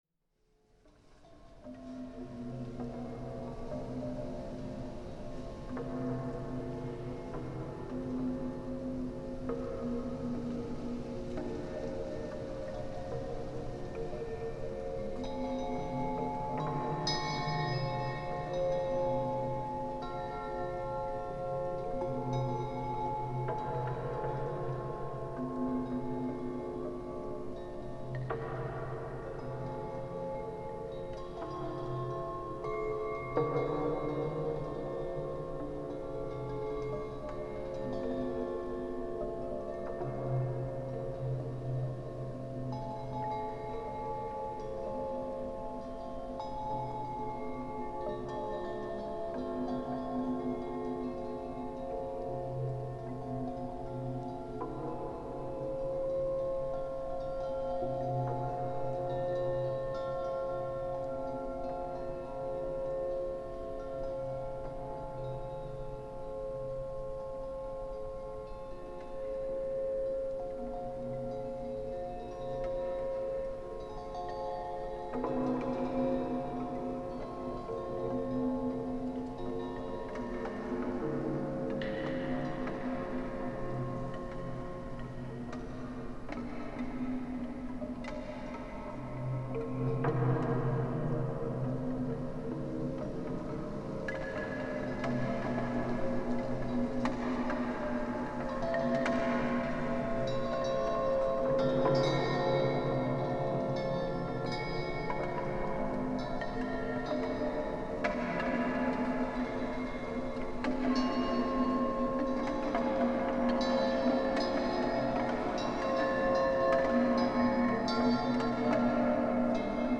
Transformed wind chimes: Nature-Symphony 20 Sound Effect — Free Download | Funny Sound Effects